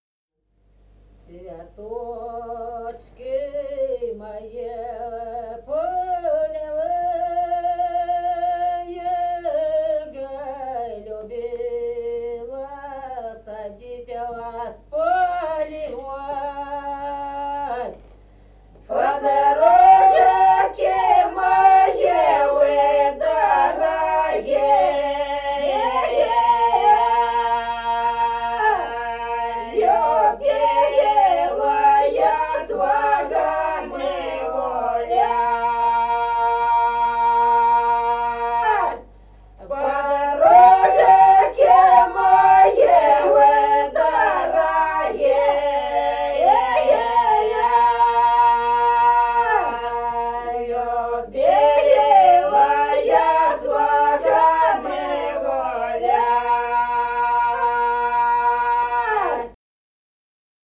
Песни села Остроглядово. Цветочки мои полевые.